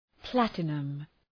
Προφορά
{‘plætənəm}